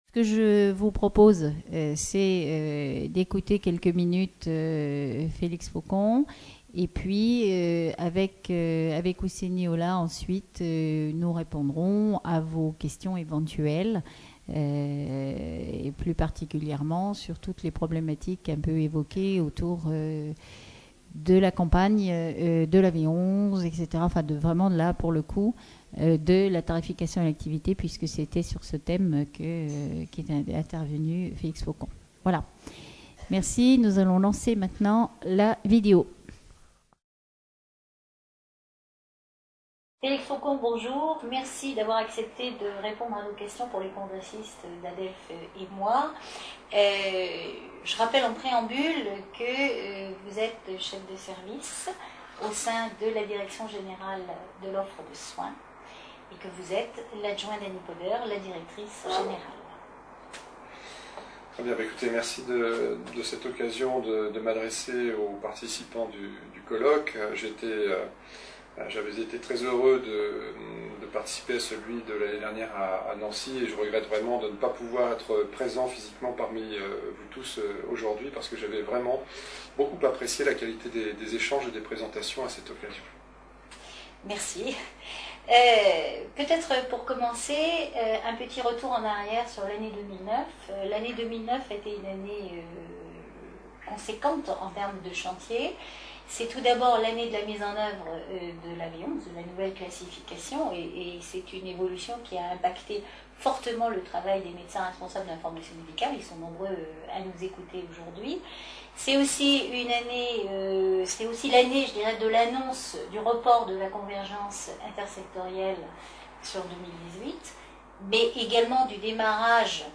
Conférence Invitée
Congrès ADELF-EMOIS 2010 Au-delà des fins budgétaires, l’exploitation des données de l’information médicale et en particulier du Programme de Médicalisation des Systèmes d’Information (PMSI) est croissante dans le cadre des prévisions d’activité et pour les décisions stratégiques d’investissement des établissements de santé.